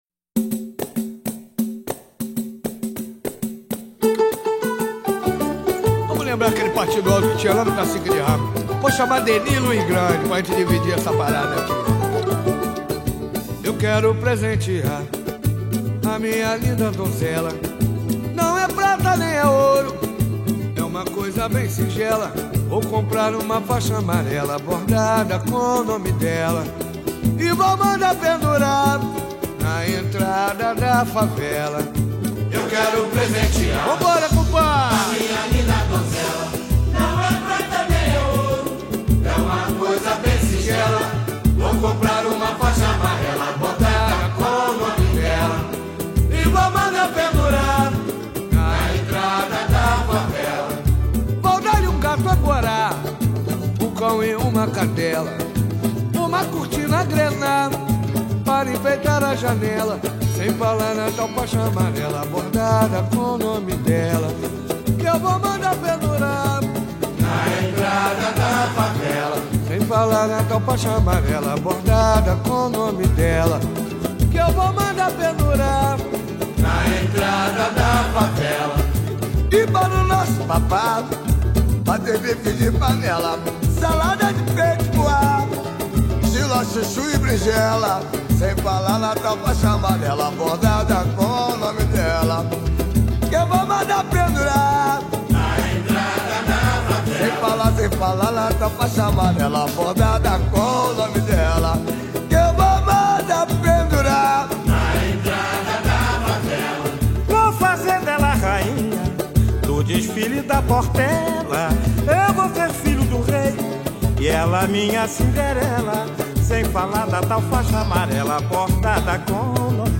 2024-10-23 19:02:15 Gênero: Samba Views